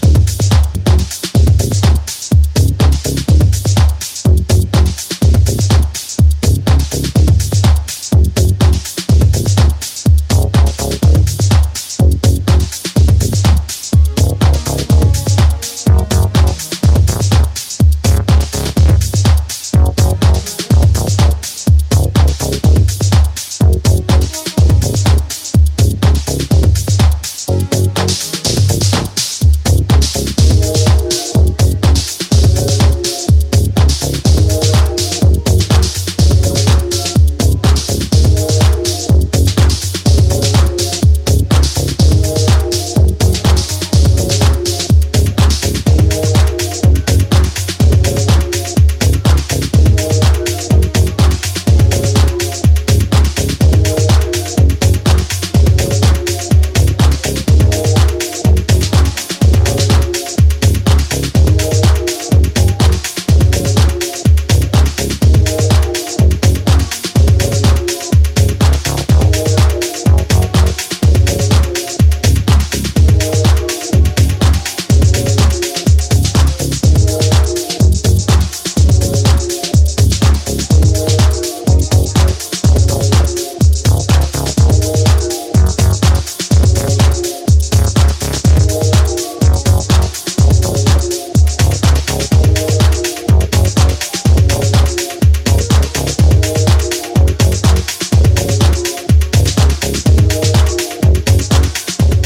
Six inexhaustible club anthems for the exhausted dj.